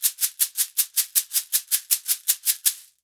Index of /90_sSampleCDs/USB Soundscan vol.36 - Percussion Loops [AKAI] 1CD/Partition A/05-80SHAKERS
80 SHAK 05.wav